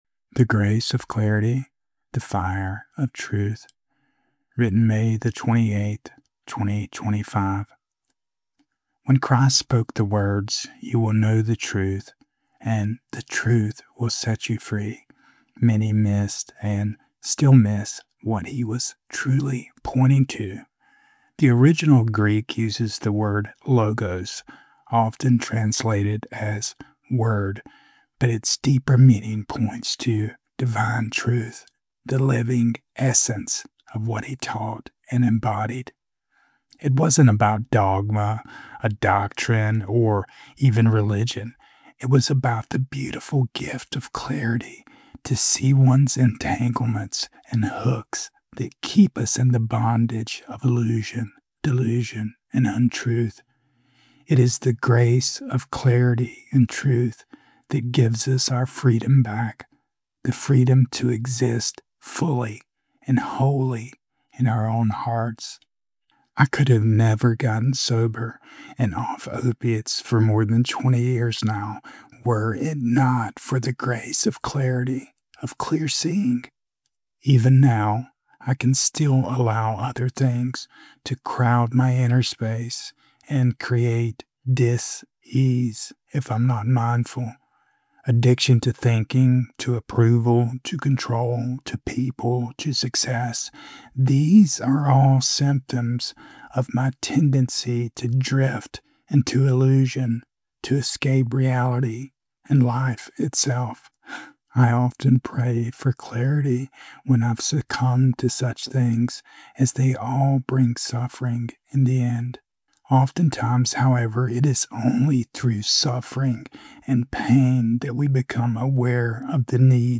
Spoken Audio